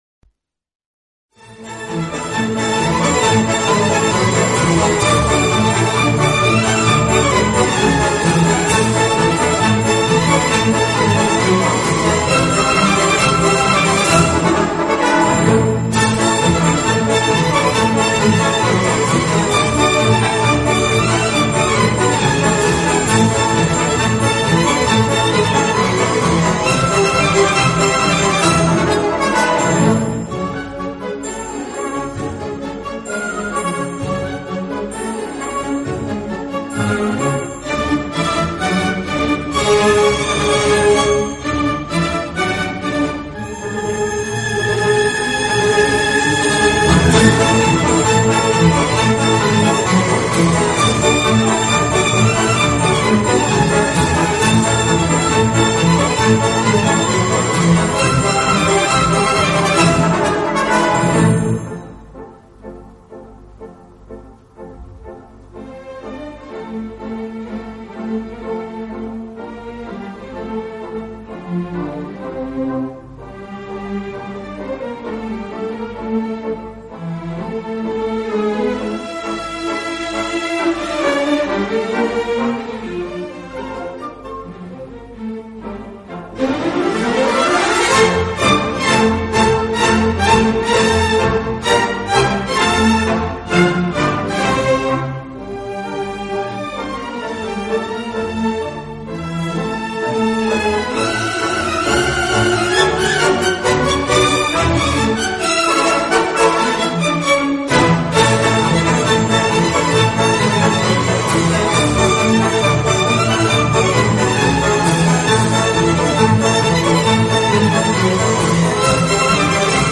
Bizet-Carmen-Opera-Complete.mp3